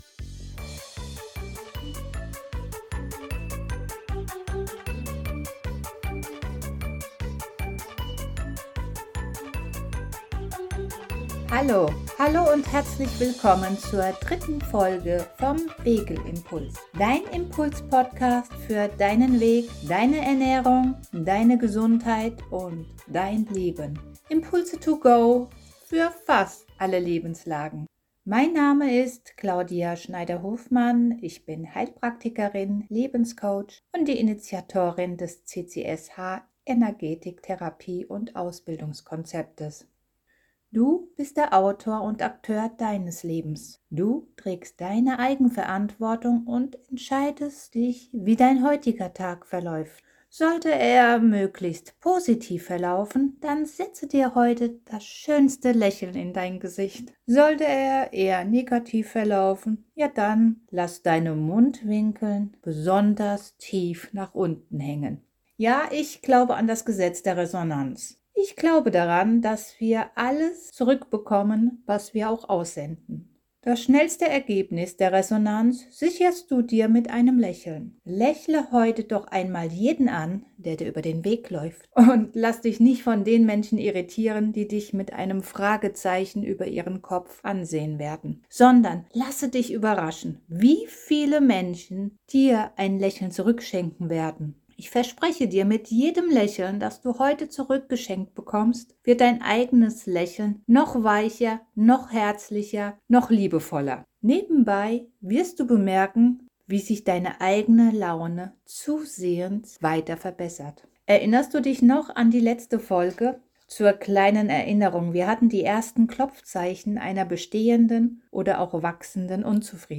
Am Ende dieser Folge erwartet dich eine kleine 10 minütige hypnotische Trancereise, eine kleine geführte Meditation.